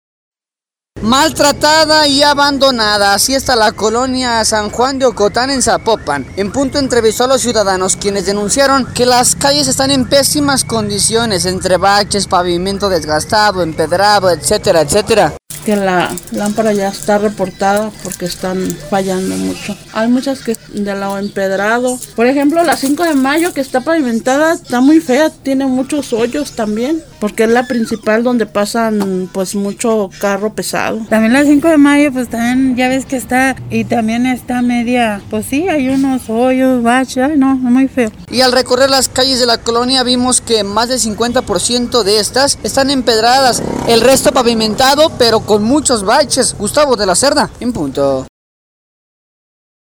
Maltratada y abandonada, así está la colonia San Juan de Ocotán, en Zapopan, En Punto entrevistó a los ciudadanos,  quienes denunciaron que las calles están en pésimas condiciones, entre baches, pavimento desgastado, empedrado, etcétera, etcétera.